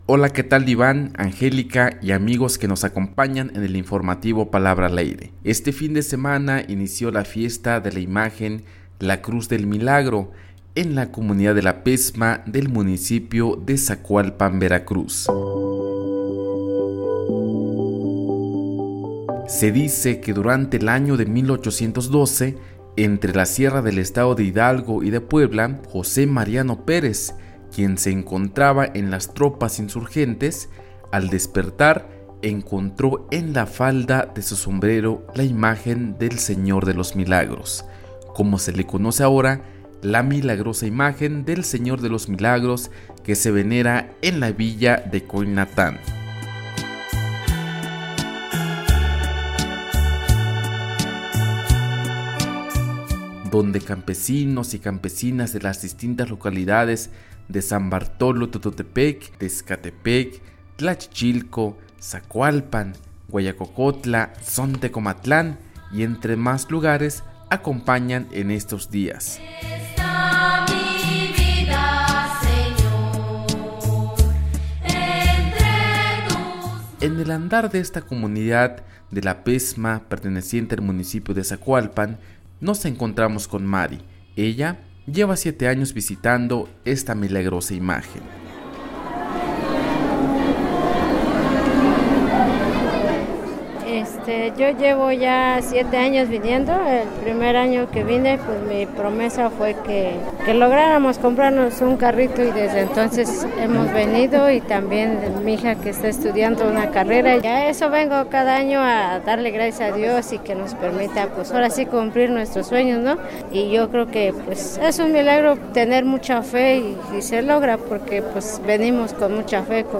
Escucha el reportaje de la fiesta de La Cruz del Milagro en La Pezma, Zacualpan